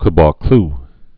(kə-bôkl, -klō)